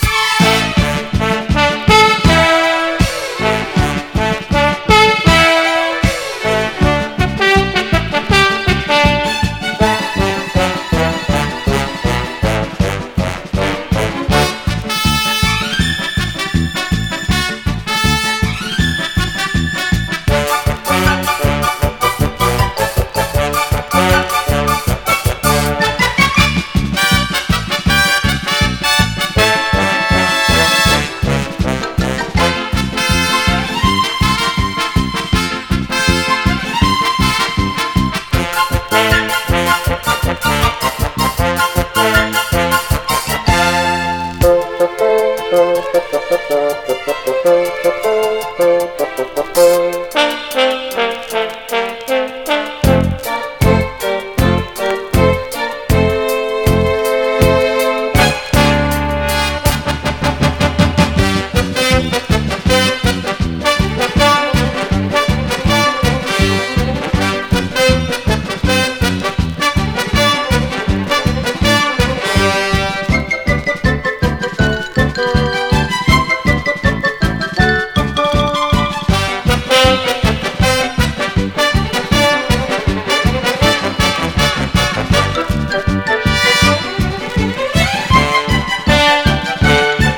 きめ細やかなストリングスとドリーミィなサウンド・アレンジは流石
多幸感たっぷりのハーモニーで舞い上がるサンシャイン・ポップな
US ORIGINAL盤MONO！